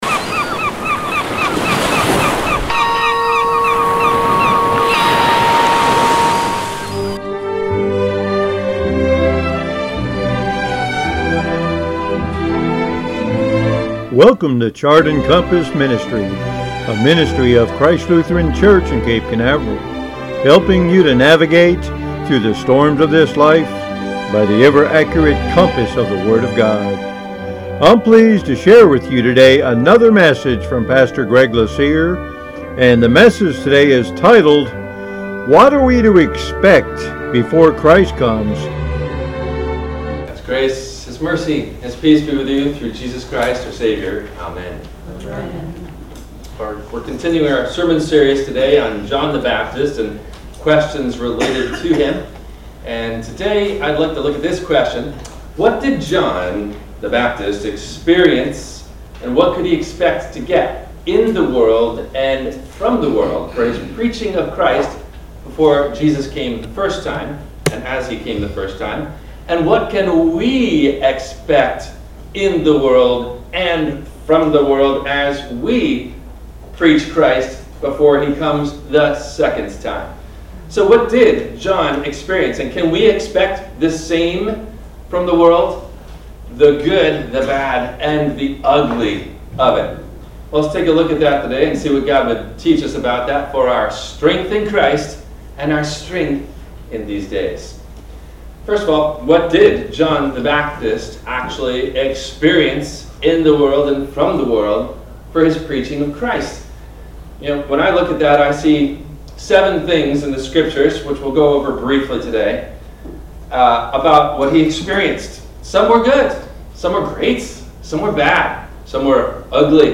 – WMIE Radio Sermon – December 26 2022